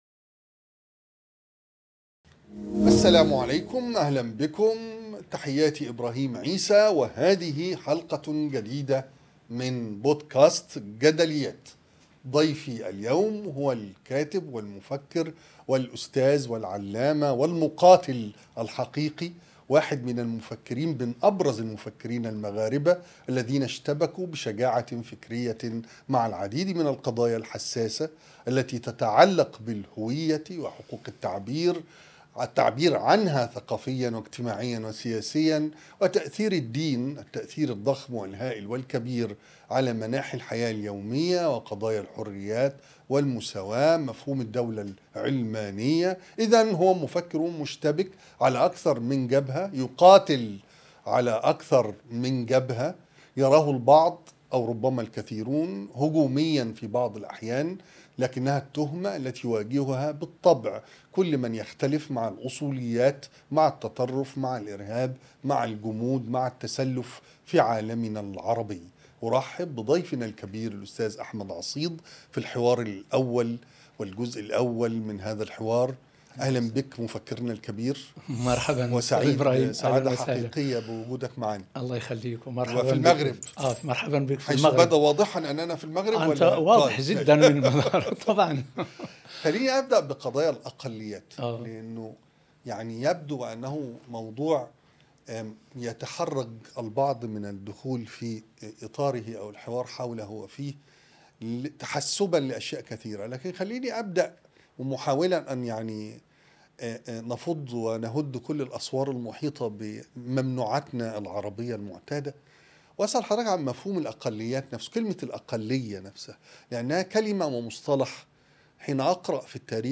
حوار مفتوح مع المفكر المغربي أحمد عصيد - جدليات مع إبراهيم عيسى